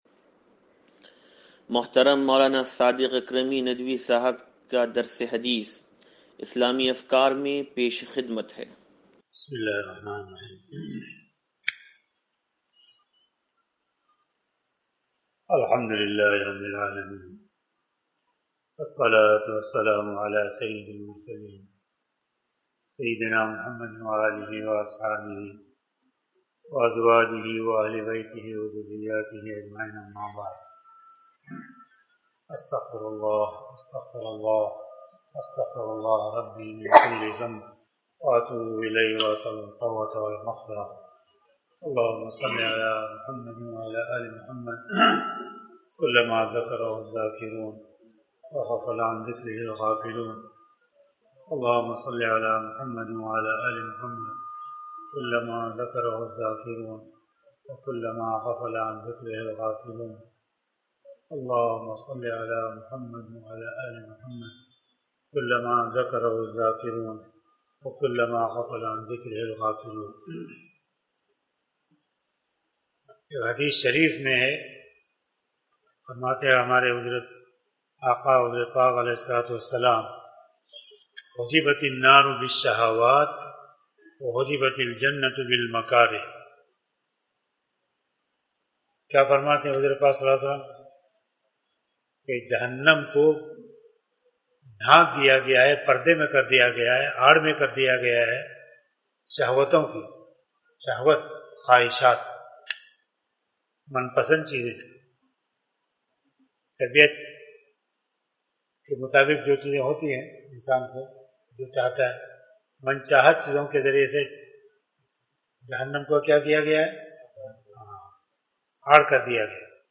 درس حدیث نمبر 0512